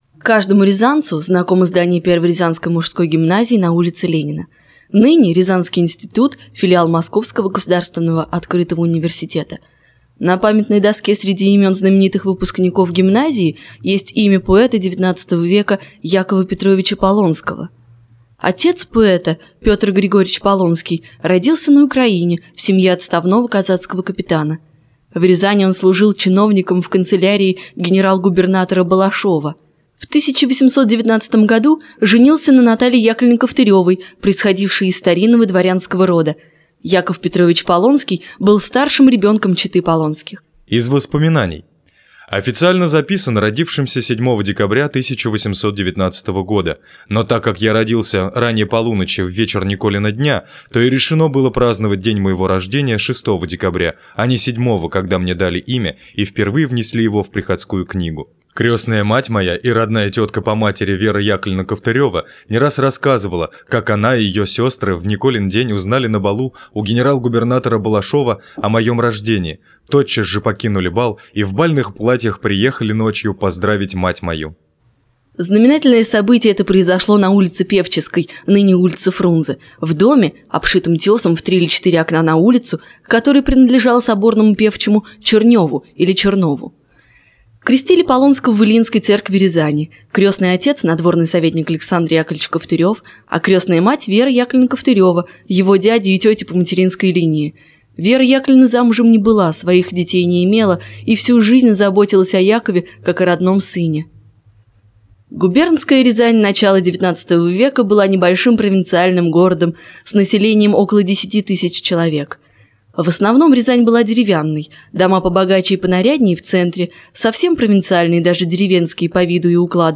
Аудиокнига Предлагаем ващему вниманию аудиокнигу посвященную жизни и творчеству Полонского Я.П. ( Текст данного материала доступен в разделе Биография ) Прослушать книгу: Скачать книгу в mp3 (36Mb).